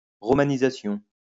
wymowa:
IPA[ʁɔ.ma.ni.za.sjɔ̃]